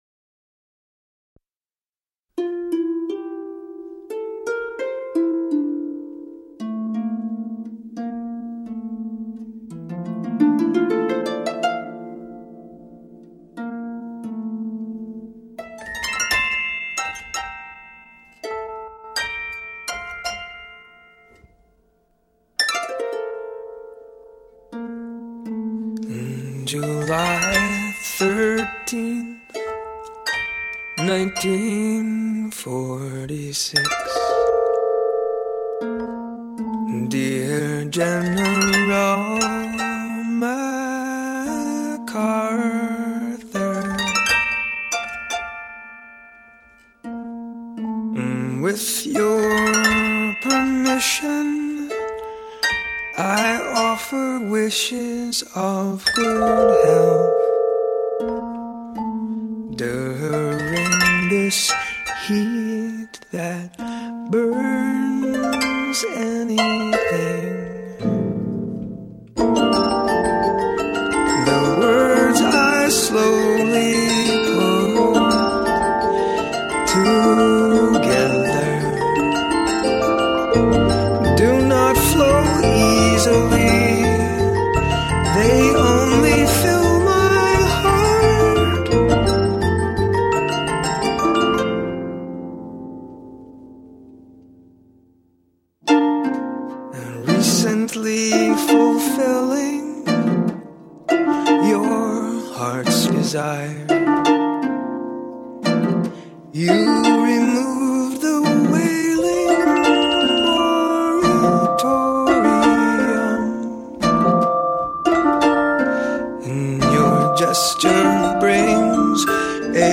Electronic House Indie Synth Techno